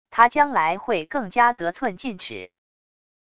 他将来会更加得寸进尺wav音频生成系统WAV Audio Player